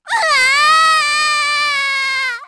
Hanus-Vox_Dead_kr.wav